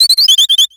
Cri de Passerouge dans Pokémon X et Y.